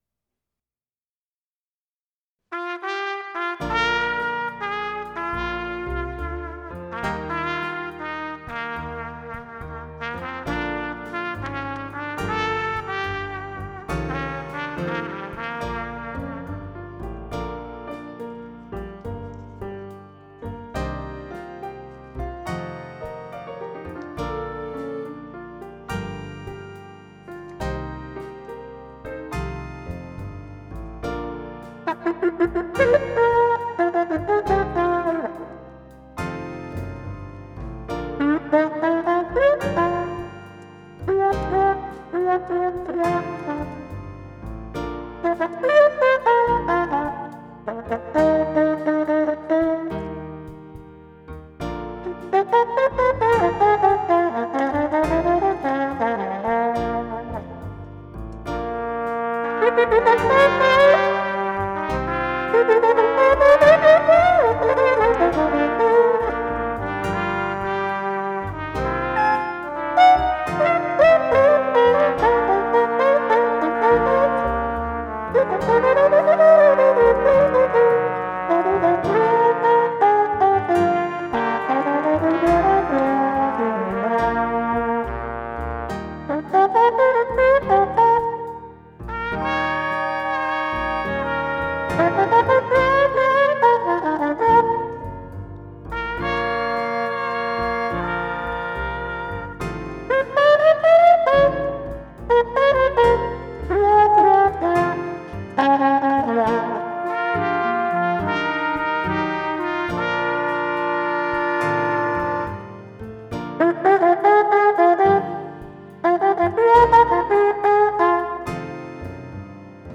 5. Play a note as you move the mute around.
If you would like to hear how it sounds, just click on MP3 below.